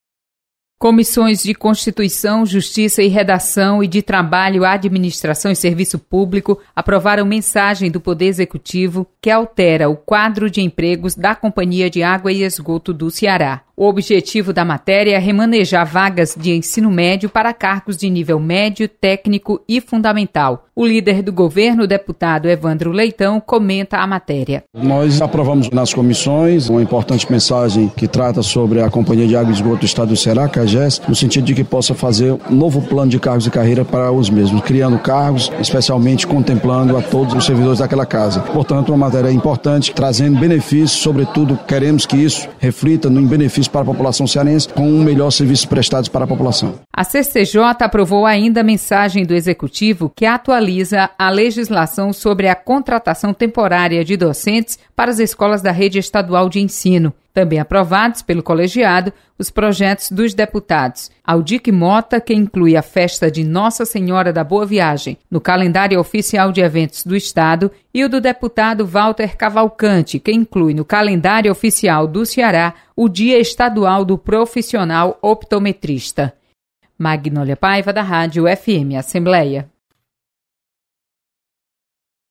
Acompanhe o resumo das comissões técnicas permanentes da Assembleia Legislativa com a repórter